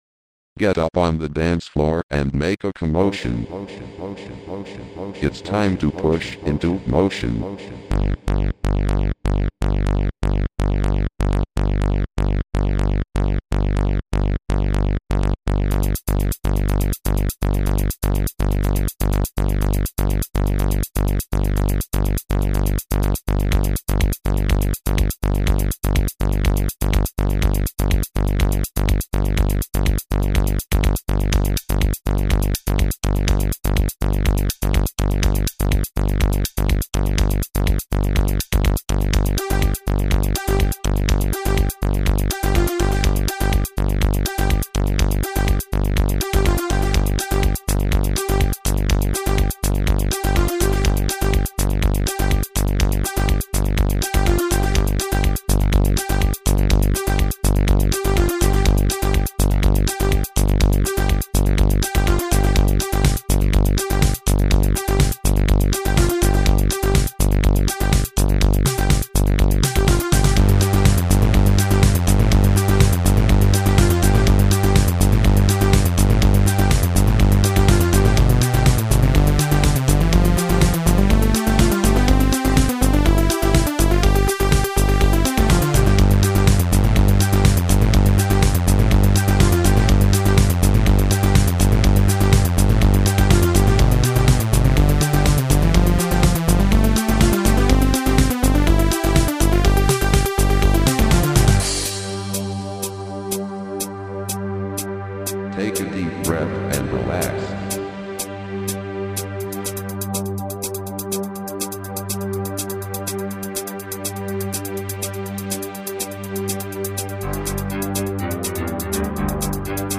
Progressive Trance - 6:31 - BPM 124
It's a happy little trance-ish tune.